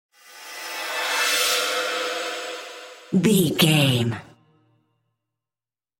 Sound Effects
Atonal
scary
ominous
eerie
synth
ambience